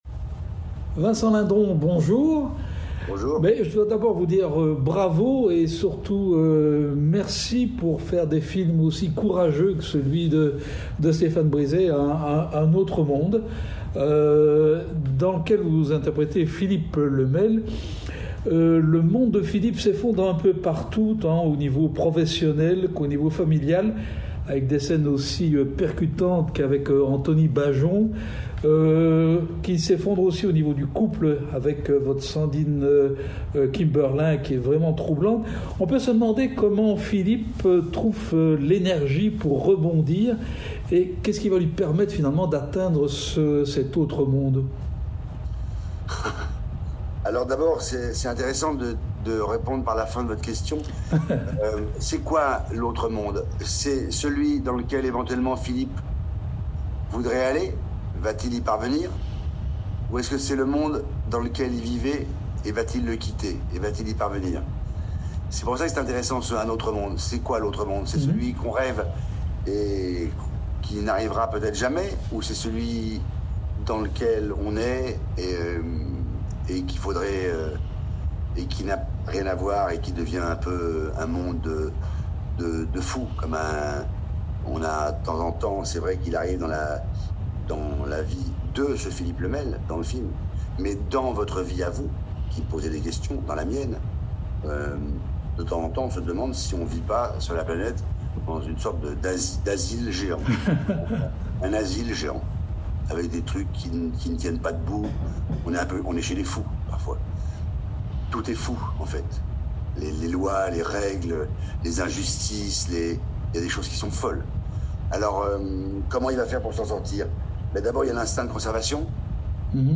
Rencontre avec un Vincent, plus transcendé que jamais.